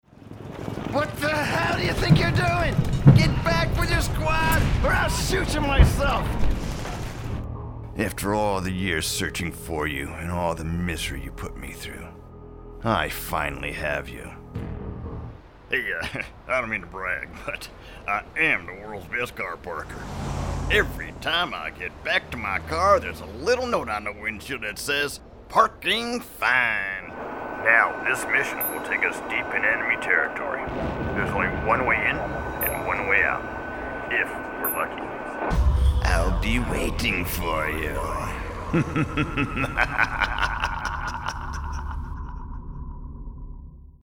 Male
English (North American)
Adult (30-50)
Full range of deep domineering authoritative to warm and heartfelt. Full range of animated voices.
Video Games